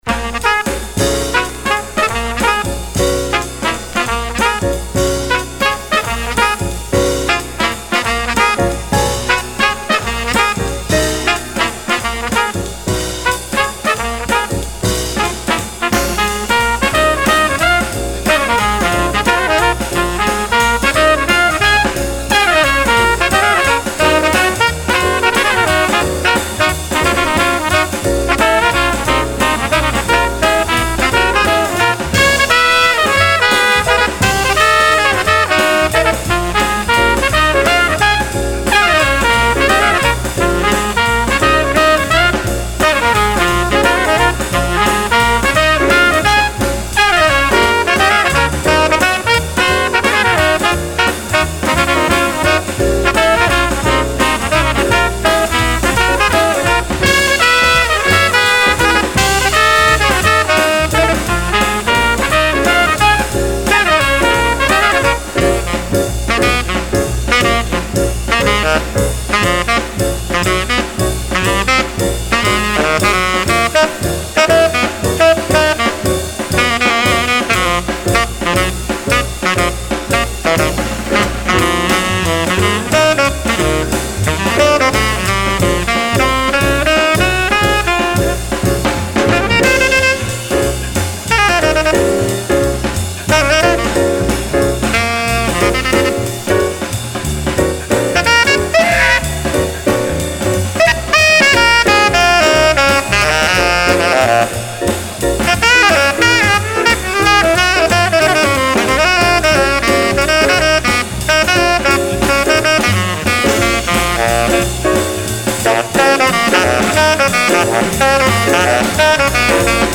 Original 1964 mono pressing